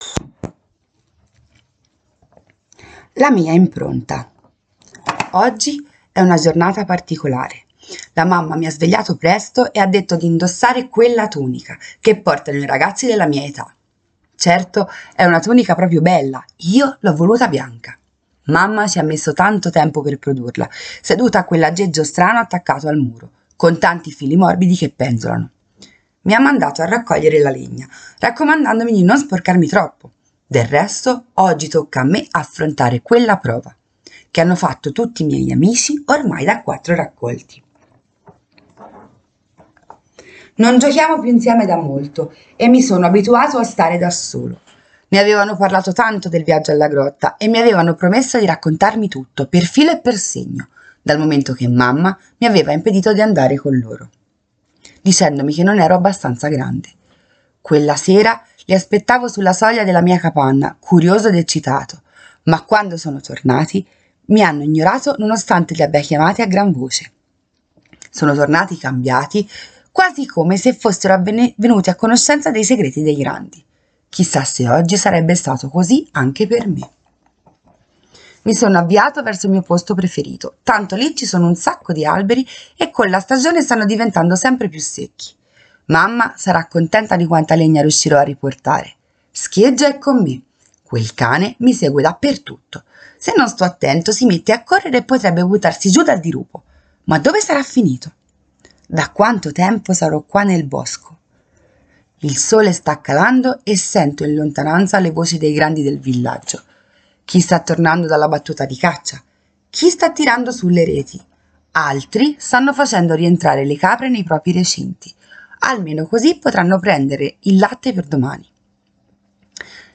PRENOTA L'attività di domenica si svolgerà dalle ore 11,00, in remoto: Leggi il racconto "La mia impronta" oppure: Ascolta il racconto "La mia impronta" e adesso tocca a te...